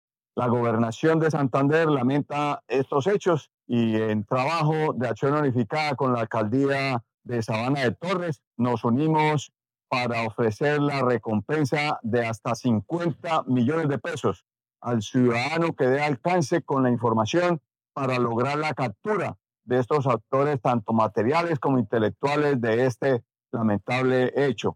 Óscar Hernández, Secretario del Interior de Santander